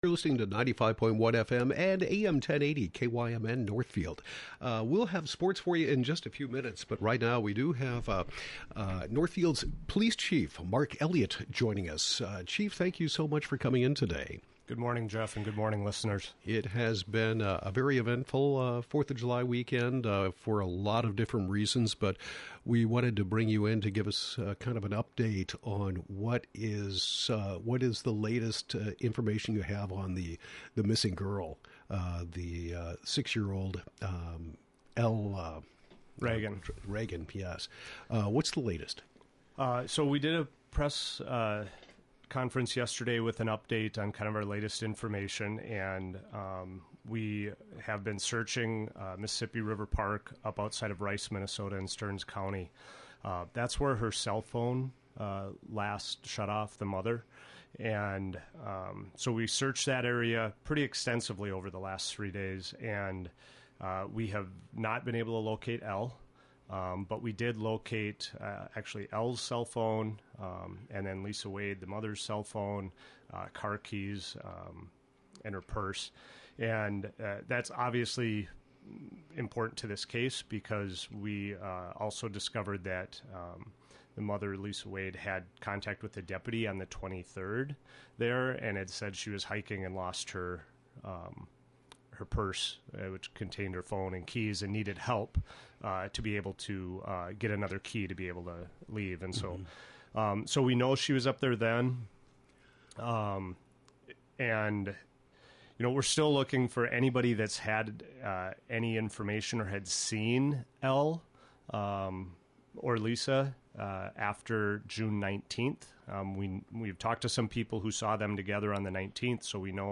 Police Chief Mark Elliott provides update on missing girl
Chief-Mark-Elliott-7-6-22.mp3